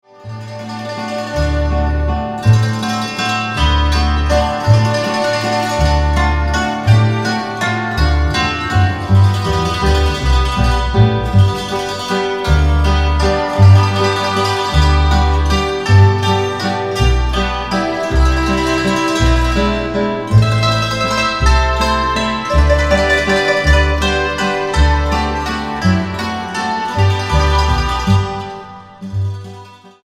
guitarra
contrabajo